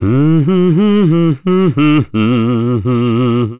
Roman-Vox_Hum.wav